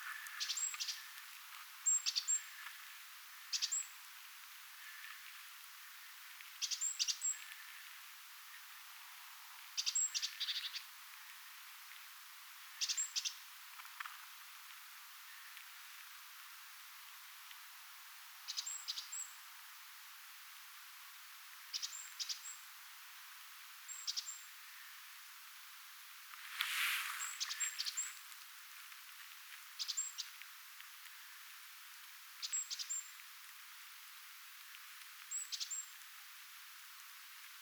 pöntön luona talitiainen
talitiainen_niiden_kahden_itsetehdyn_linnunponton_kohdalla_lahella_vanhaa_lintutornia.mp3